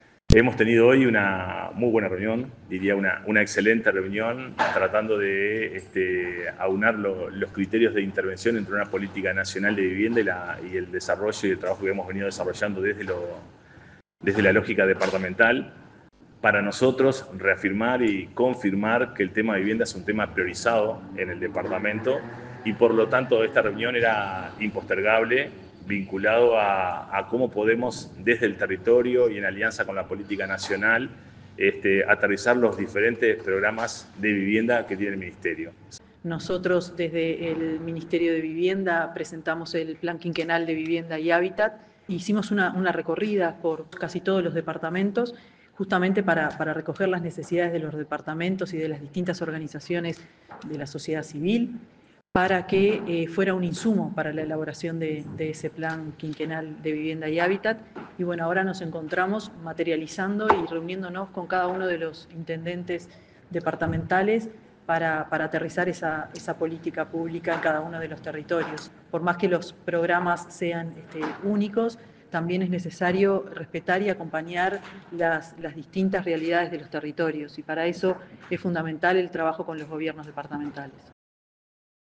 Palabras de la ministra de Vivienda y del intendente de Río Negro
Palabras de la ministra de Vivienda y del intendente de Río Negro 30/10/2025 Compartir Facebook X Copiar enlace WhatsApp LinkedIn La ministra de Vivienda y Ordenamiento Territorial, Tamara Paseyro, junto con el intendente de Río Negro, Guillermo Levratto, brindaron una conferencia de prensa acerca de la visita de la secretaria de Estado al departamento y de una posterior reunión.